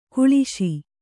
♪ kuḷiśi